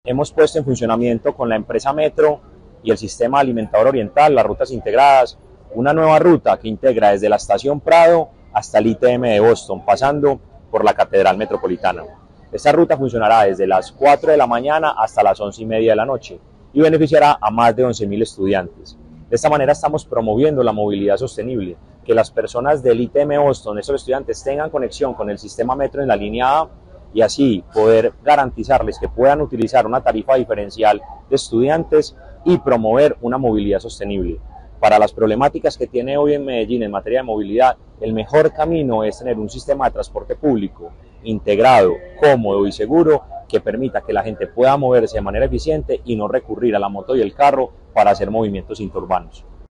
Palabras de Mateo González, Secretario de Movilidad